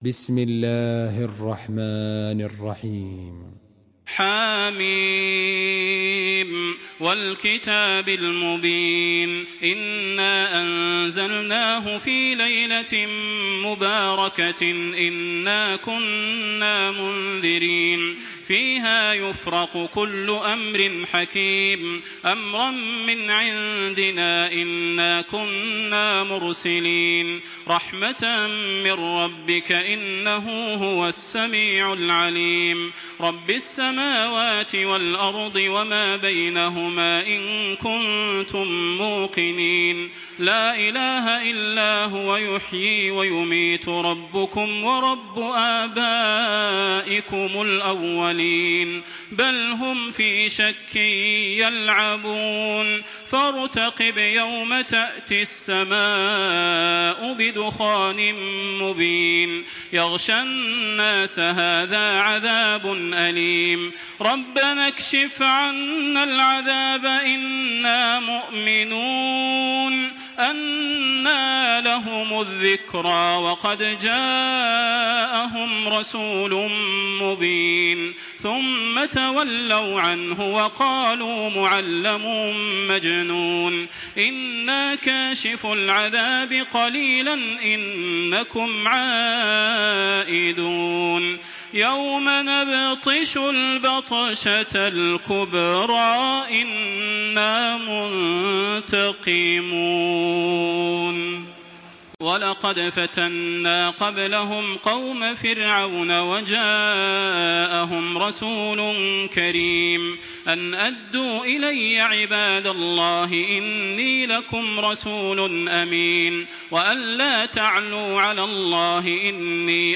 تحميل سورة الدخان بصوت تراويح الحرم المكي 1426
مرتل